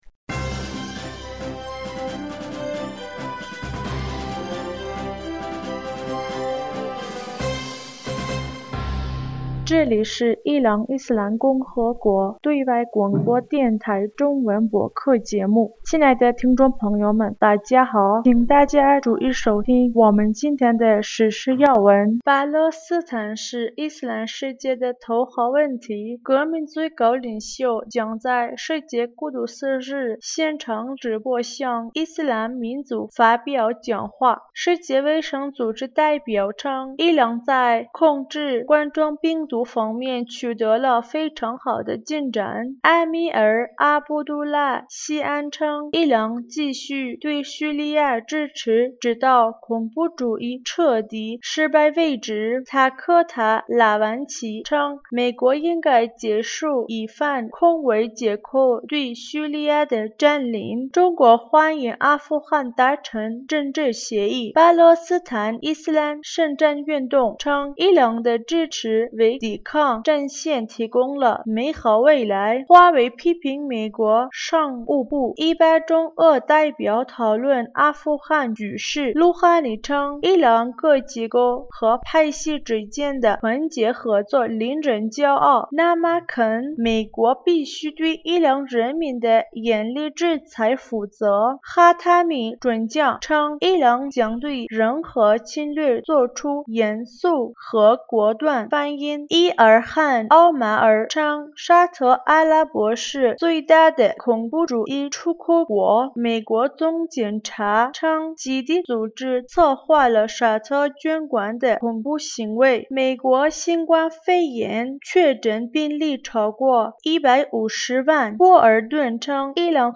2020年5月19日 新闻